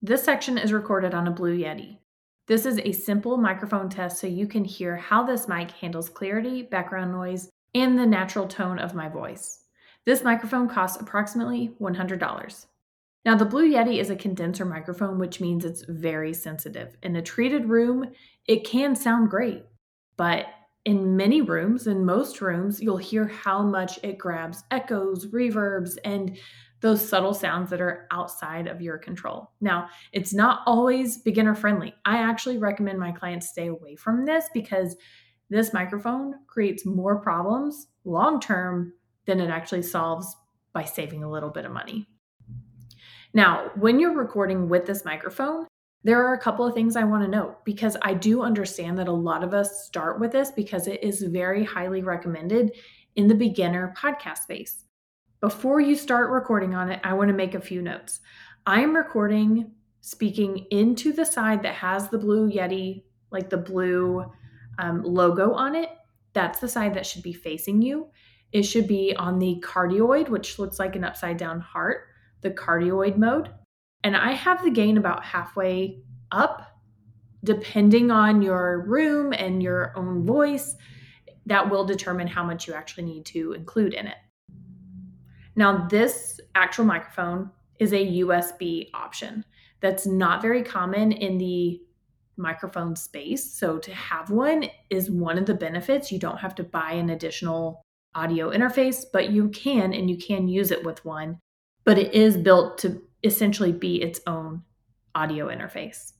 While we don’t fault podcasters for using this, we don’t recommend it on our own gear list due to the compression it generates and audio it produces.
Blue-Yeti-Microphone.mp3